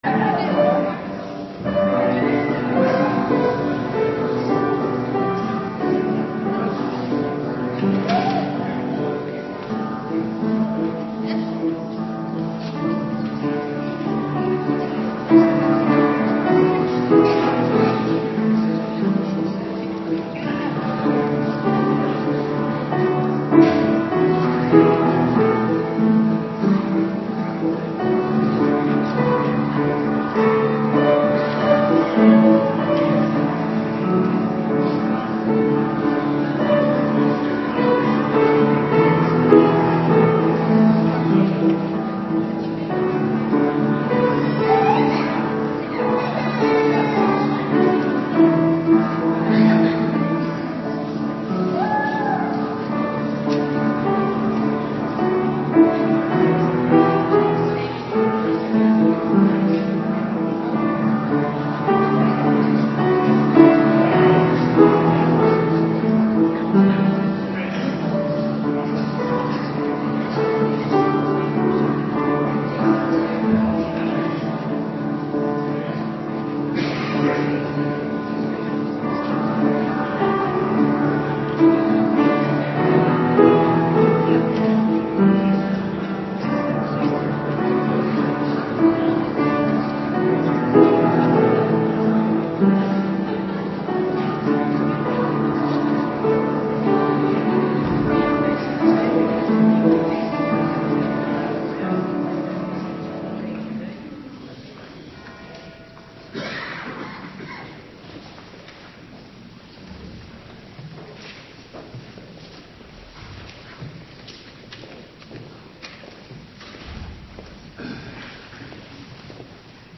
Morgendienst 6 april 2026